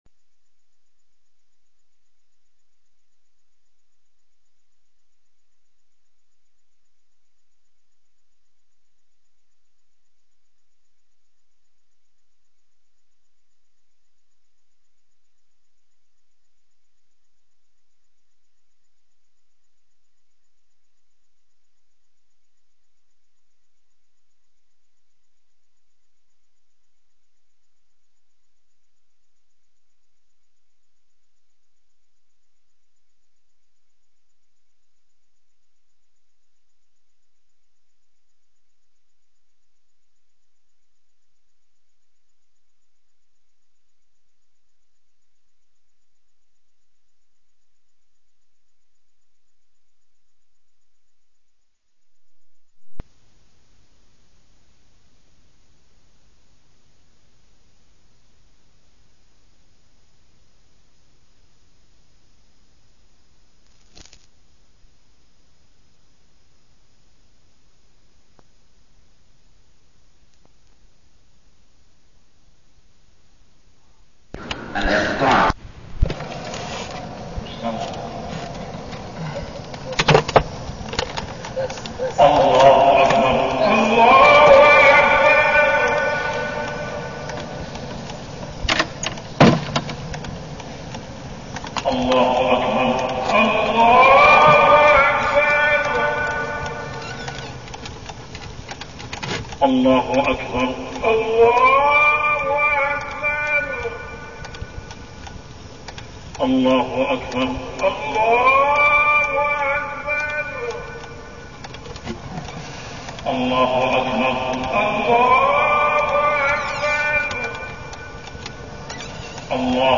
تاريخ النشر ١ جمادى الآخرة ١٤١٤ هـ المكان: المسجد الحرام الشيخ: محمد بن عبد الله السبيل محمد بن عبد الله السبيل أسباب منع القطر